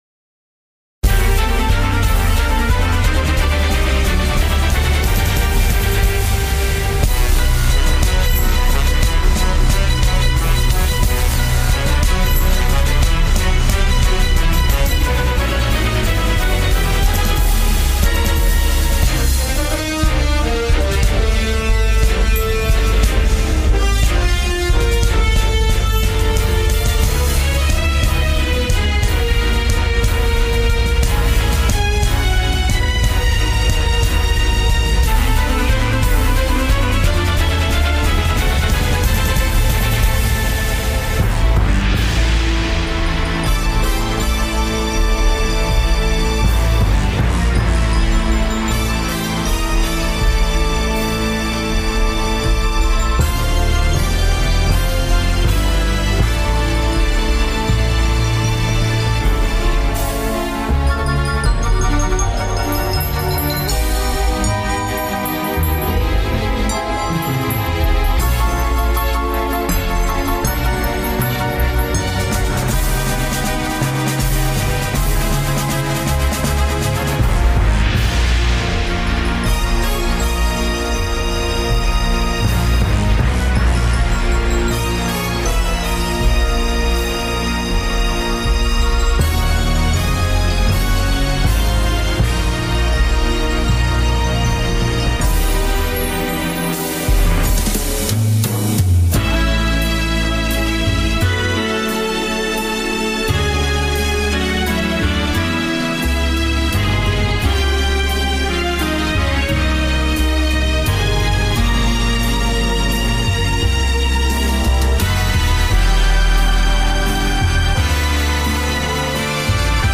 1- Cinematic | Original Composition